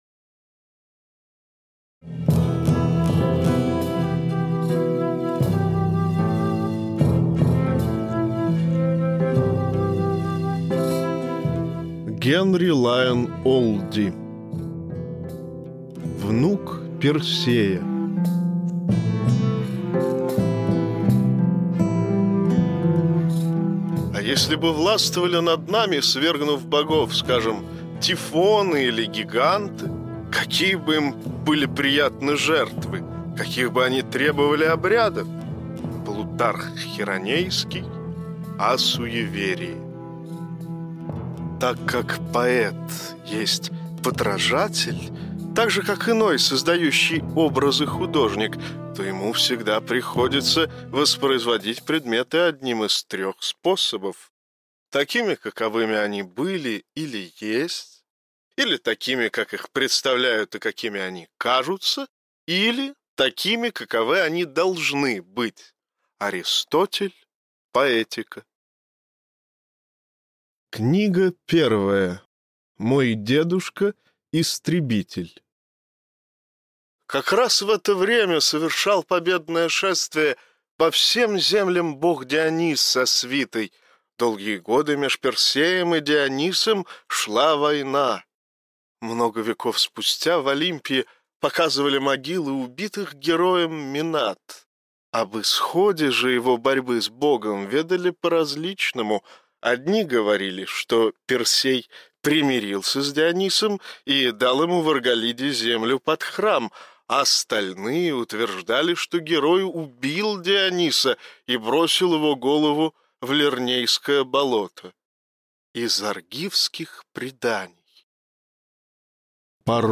Аудиокнига Внук Персея. Мой дедушка – Истребитель | Библиотека аудиокниг